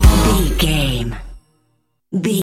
Aeolian/Minor
A♭
Fast
drum machine
synthesiser